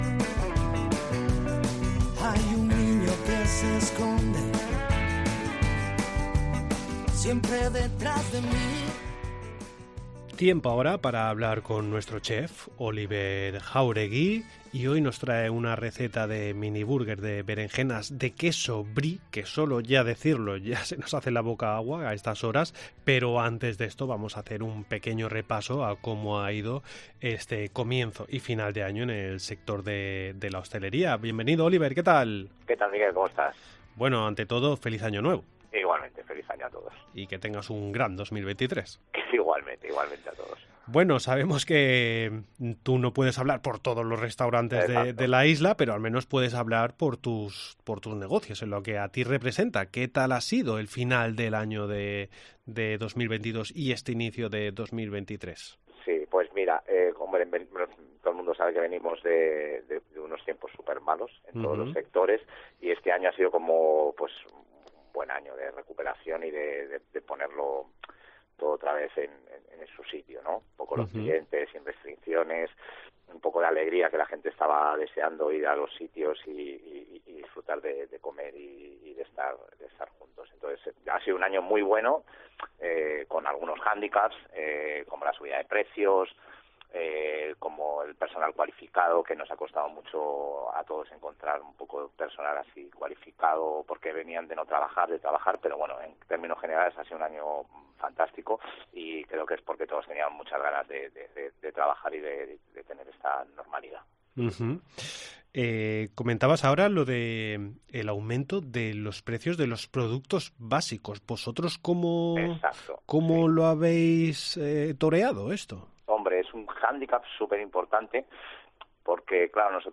Entrevista en La Mañana en COPE Más Mallorca, lunes 02 de enero de 2023.